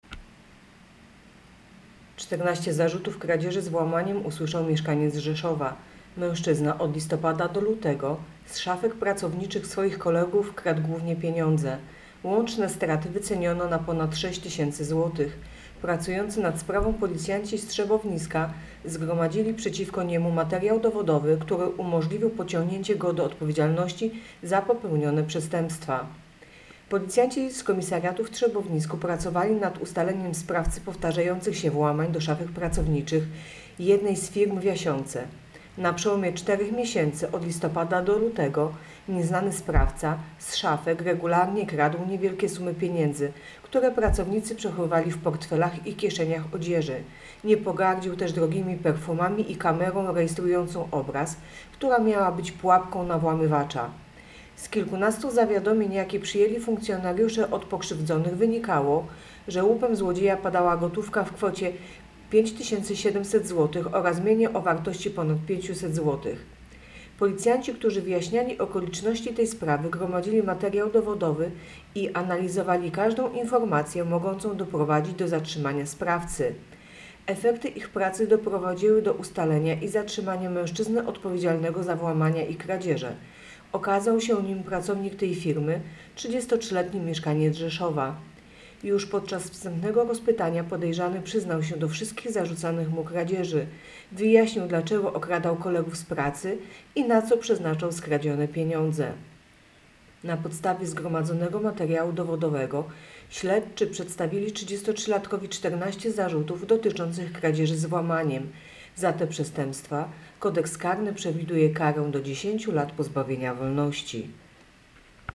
Opis nagrania: Nagranie informacji pt. Okradał swoich kolegów z pracy - usłyszał 14 zarzutów.